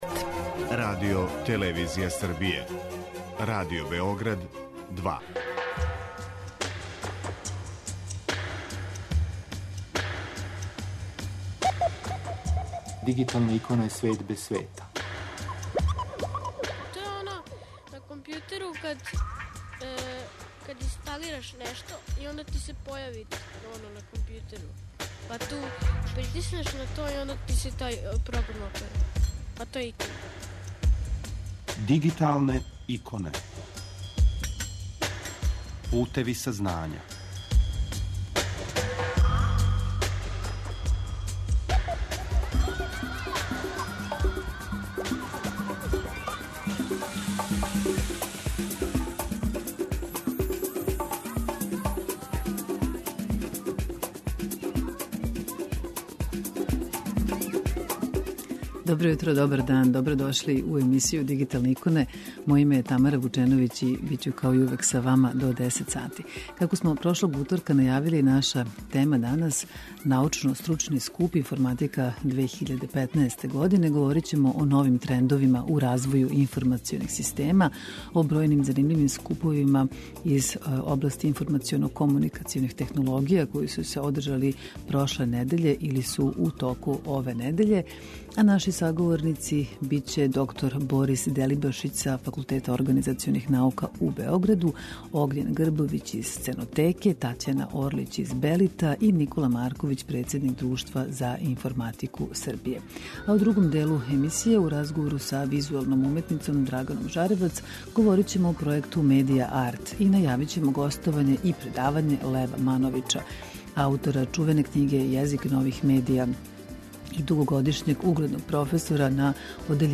Емисија Дигиталне иконе покренута je 2002. године, а емитује се сваког уторка на таласима Радио Београда 2 од 9 до 10 сати.